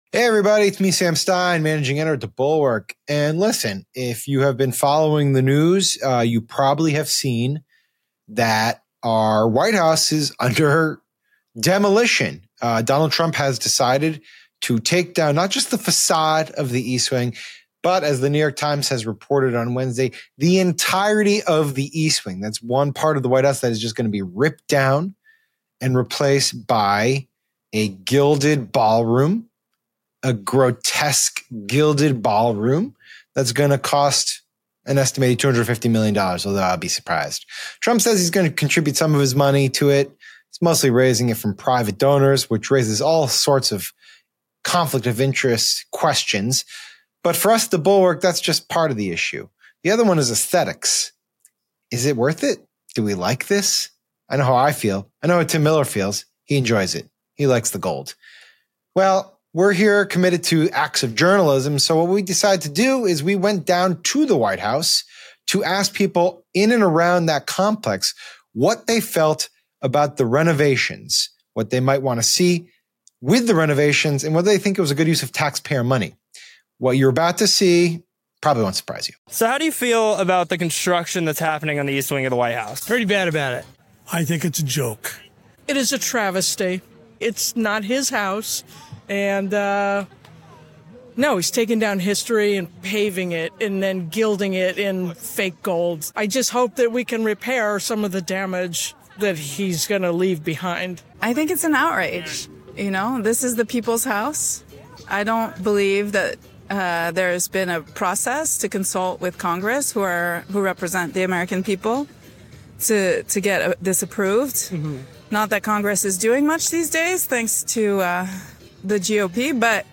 The Bulwark hit the streets of D.C. to ask what people think about Trump demolishing the east wing of the White House to build a $250 million gold ballroom.